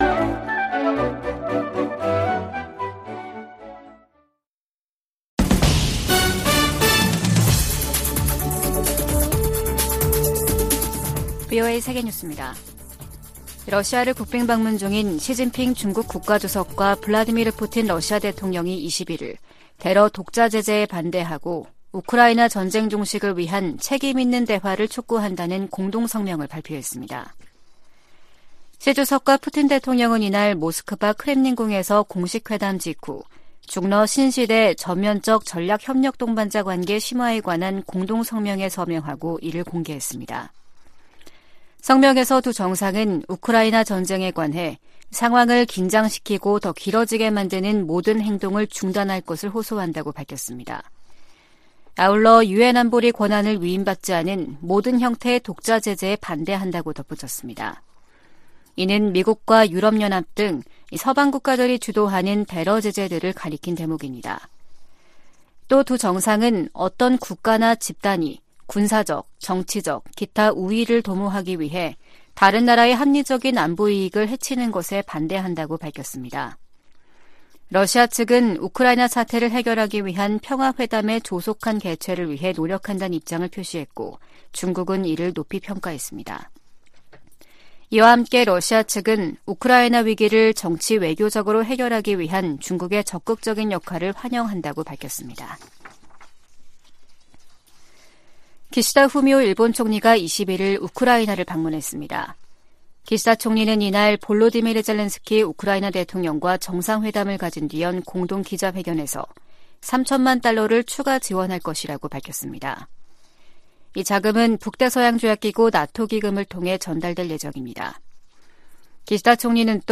VOA 한국어 아침 뉴스 프로그램 '워싱턴 뉴스 광장' 2023년 3월 22일 방송입니다. 북한이 모의 핵탄두를 탑재한 미사일 공중폭발 시험훈련에 성공했다고 밝히면서 전술핵 위협이 한층 현실화했다는 평가가 나옵니다. 유엔 안전보장이사회가 북한의 대륙간탄도미사일(ICBM) 발사에 대응한 공개회의를 개최하고 북한을 규탄했습니다. 북한에서 살인과 고문, 인신매매 등 광범위한 인권 유린 행위가 여전히 자행되고 있다고 미 국무부가 밝혔습니다.